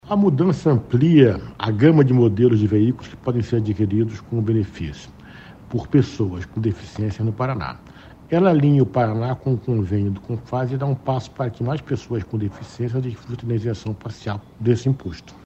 Sonora do secretário Estadual da Fazenda, Renê Garcia Junior, sobre a elevação do teto de isenção parcial do ICMS de veículos para PCD